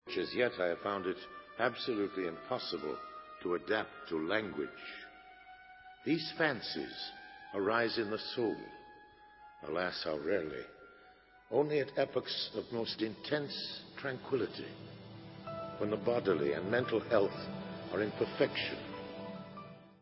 sledovat novinky v oddělení Pop/Symphonic